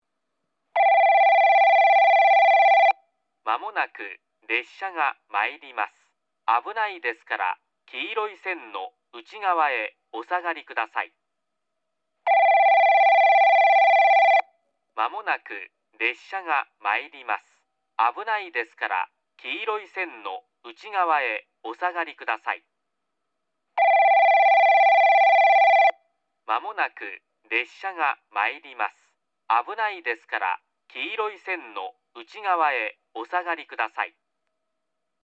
接近放送　男声 放送は九州カンノ型Cです。
スピーカーはＵＮＩ‐ＰＥＸラッパです。